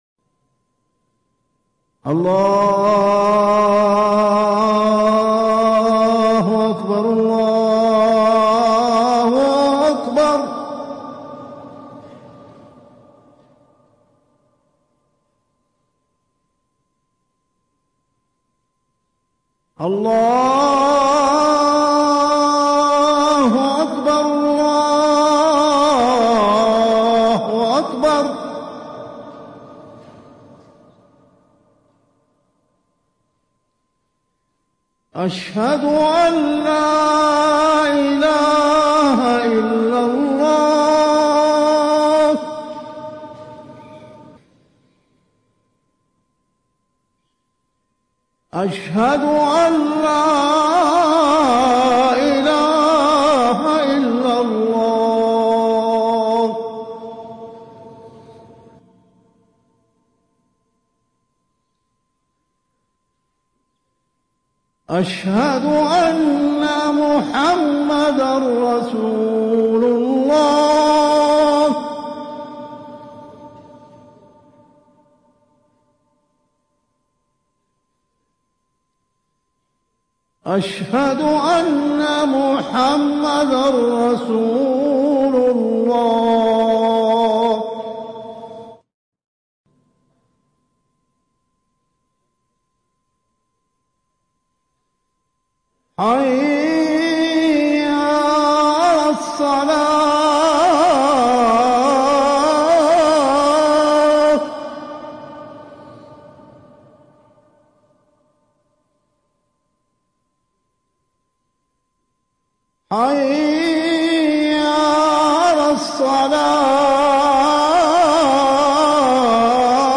المكان: المسجد النبوي الشيخ
أذان